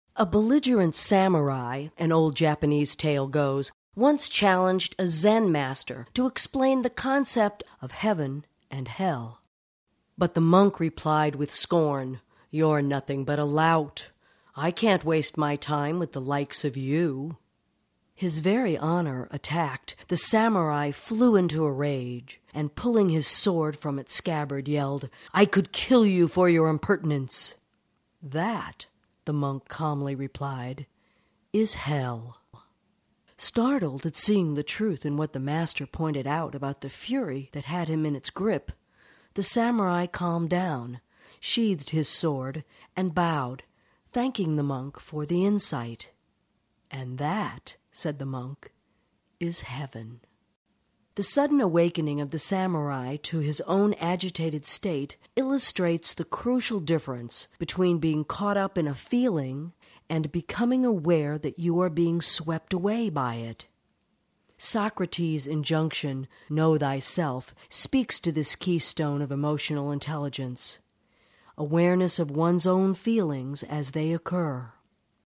I'm a very experienced & versatile voice actor with a pro-level home studio.
Audio Book - Non-Fiction Demo